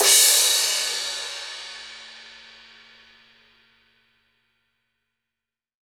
• Drum Crash Sample C# Key 04.wav
Royality free drum crash sample tuned to the C# note. Loudest frequency: 6513Hz
drum-crash-sample-c-sharp-key-04-Lq2.wav